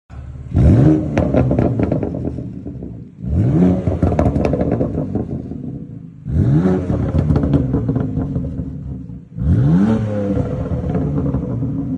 Der bekannte Remus-Klang, der Köpfe verdreht.